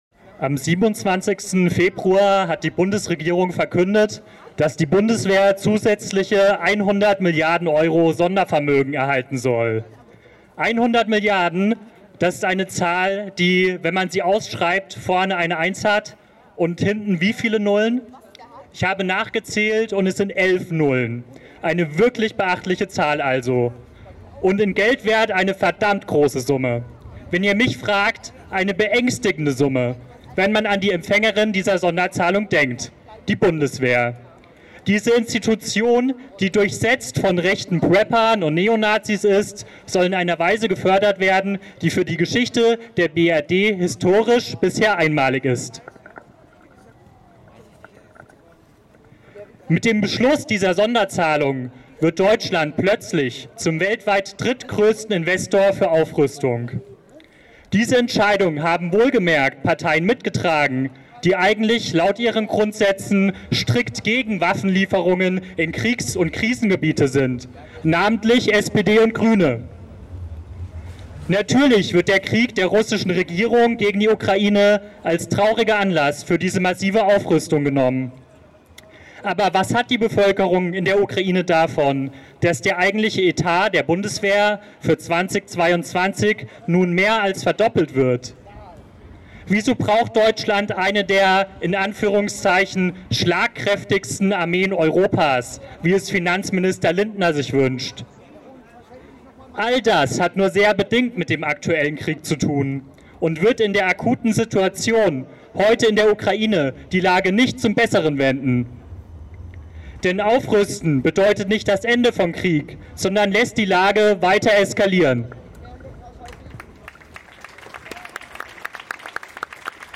Eine Kundgebung auf dem Platz der Alten Synagoge.